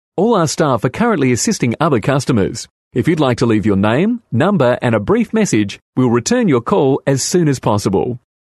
• Professional male and female voice talent